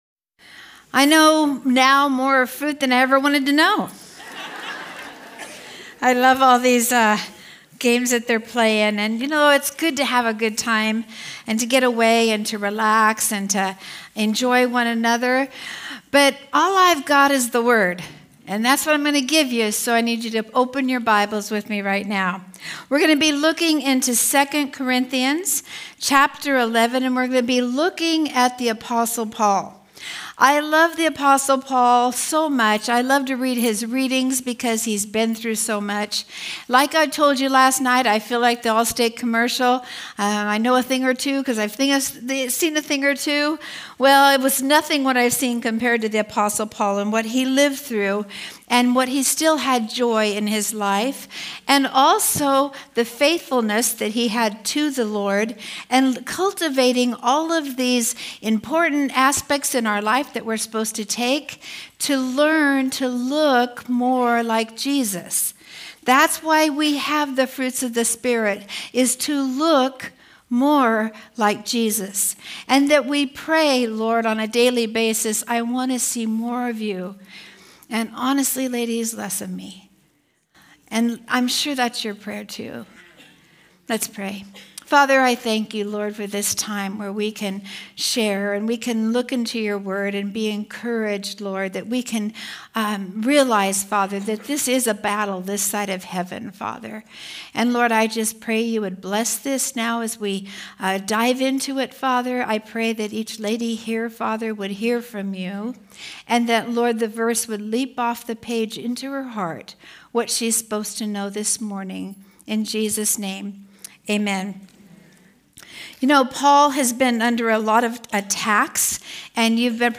Home » Sermons » Fruit of Patience